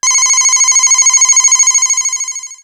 Ringing09.wav